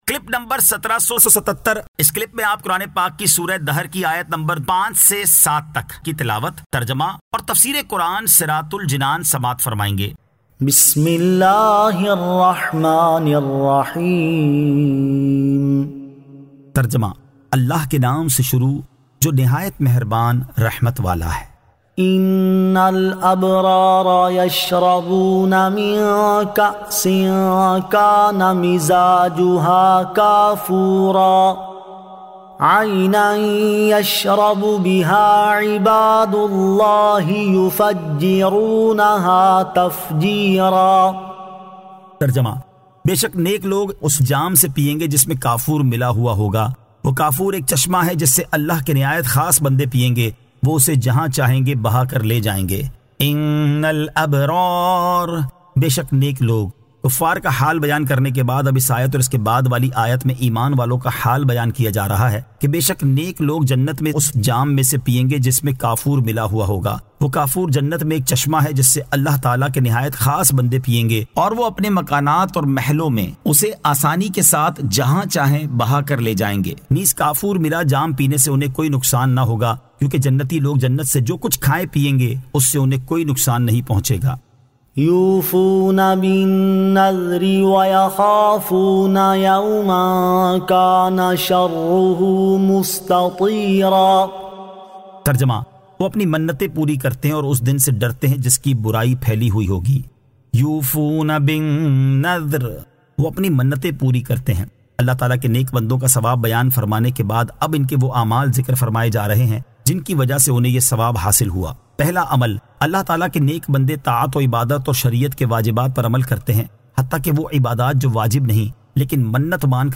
Surah Ad-Dahr 05 To 07 Tilawat , Tarjama , Tafseer